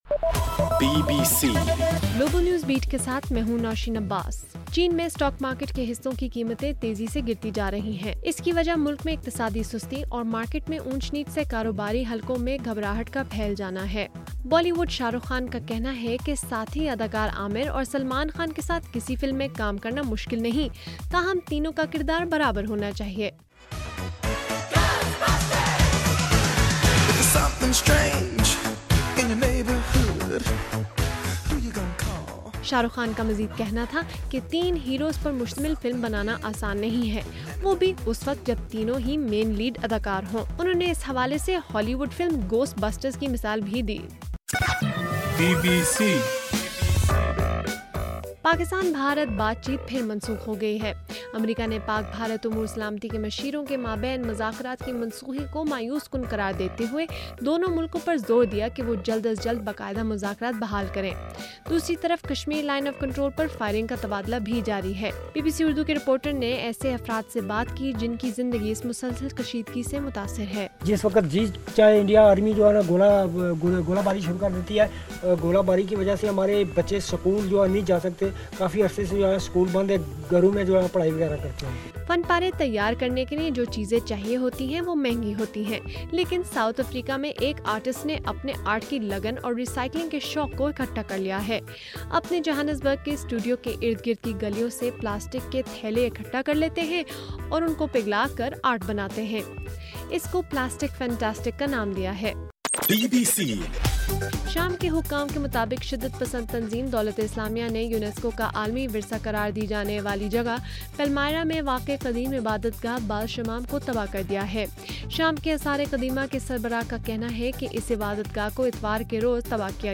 اگست 25: صبح 1 بجے کا گلوبل نیوز بیٹ بُلیٹن